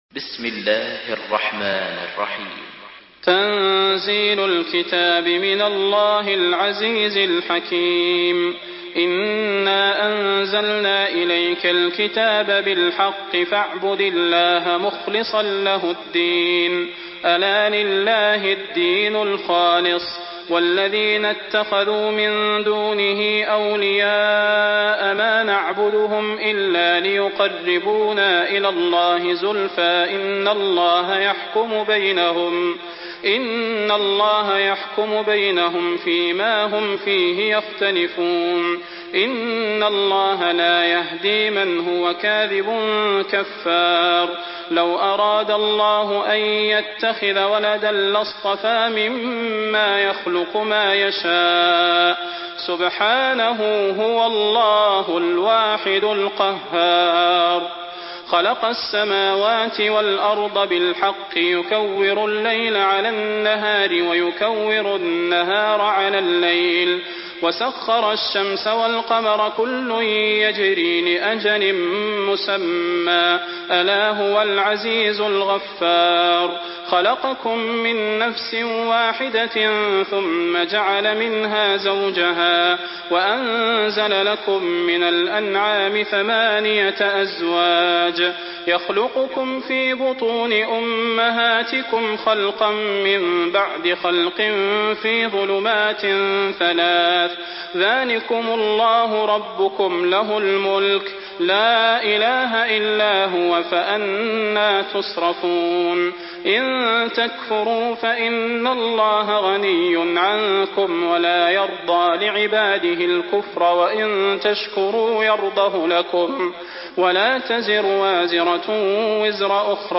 Surah Az-zumar MP3 by Salah Al Budair in Hafs An Asim narration.
Murattal